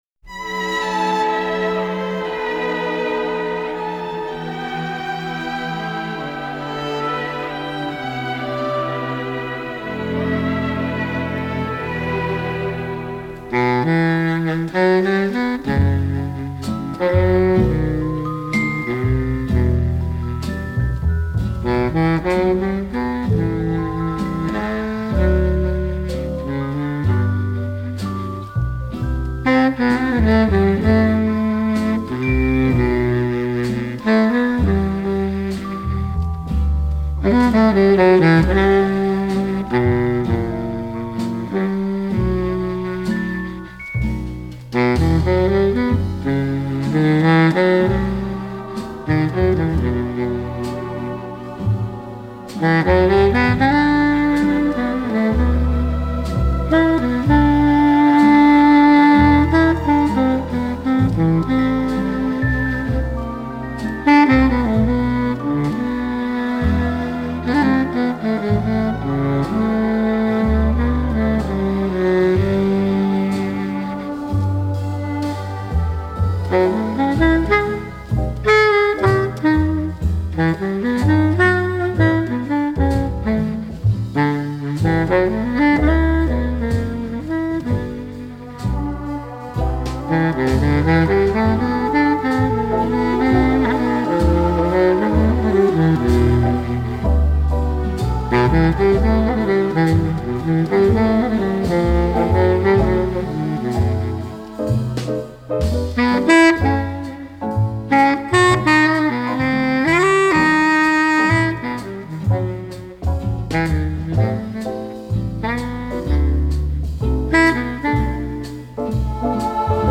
И немного для вечернего настроения джазовой музыки.